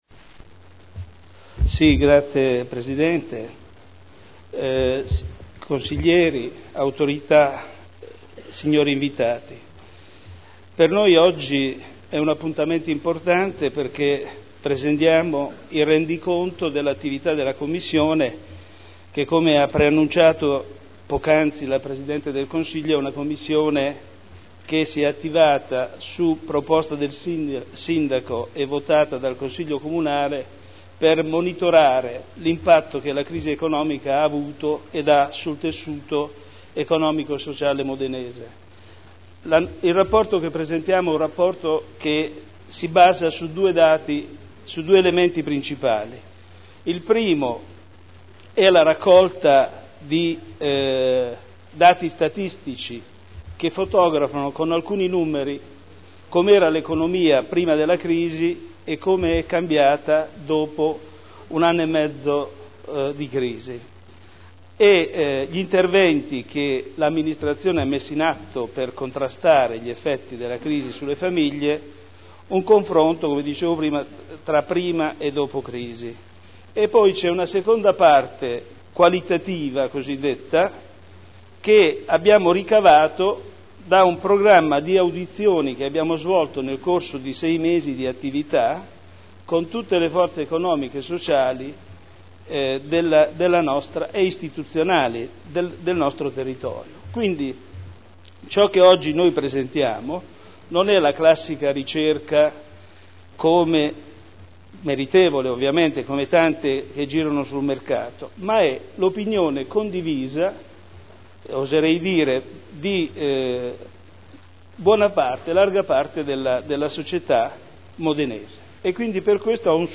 Seduta del 28/06/2010